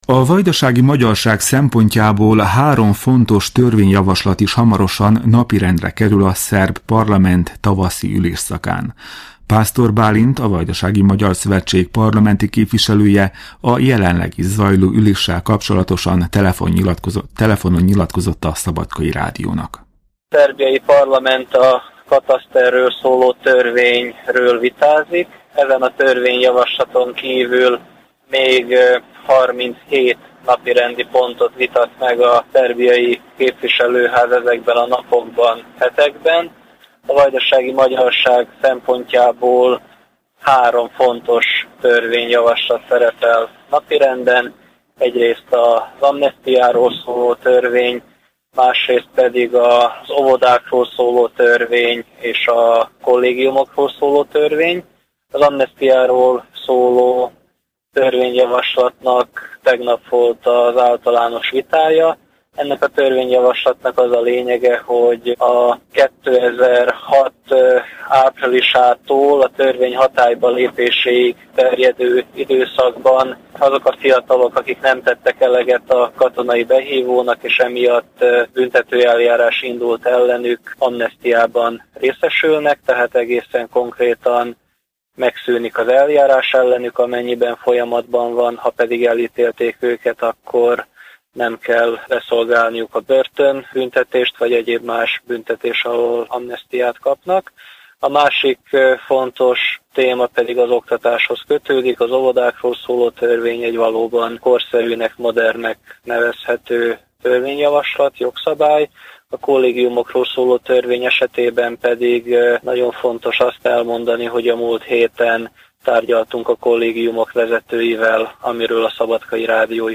Híradó - Srebrenicáról szóló parlamenti határozat